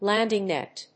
アクセントlánding nèt